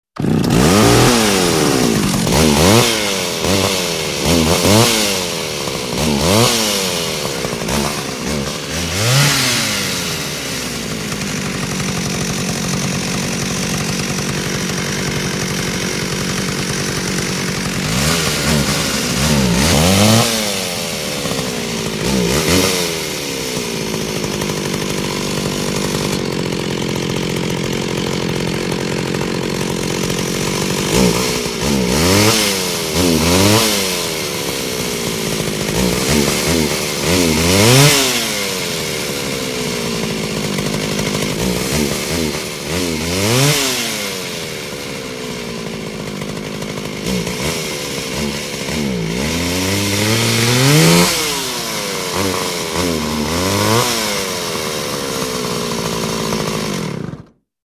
Звуки мопеда, скутера
Звук мотоцикла на месте с газом